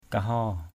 /ka-hɔ:/ (d.) người Kaho (K’ho) = la peuplade des Koho.